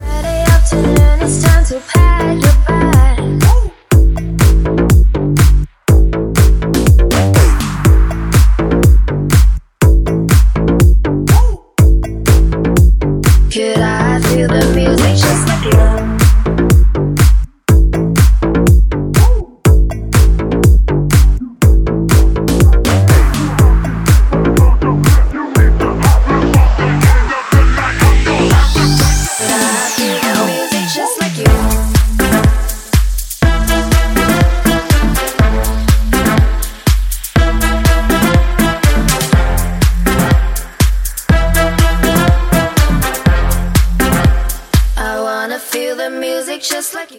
громкие
remix
клубная музыка
Стили: G-House, Bass House